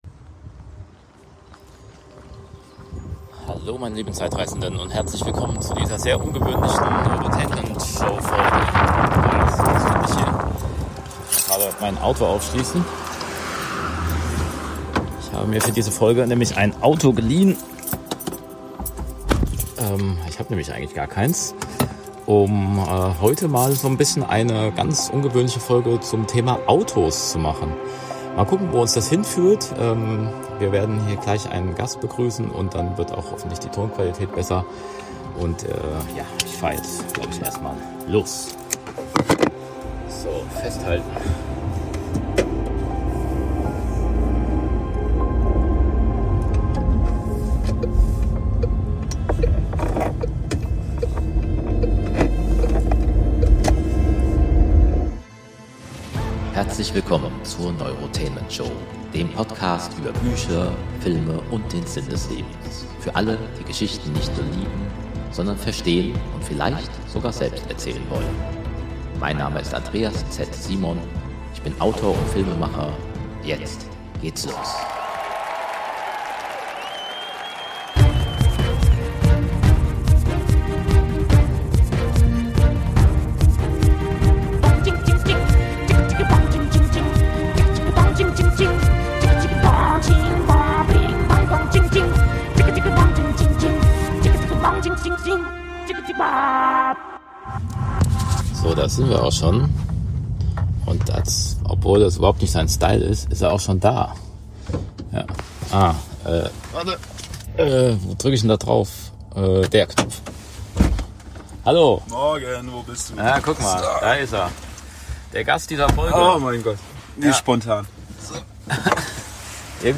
Eine Auto-Spezialfolge der Neurotainment Show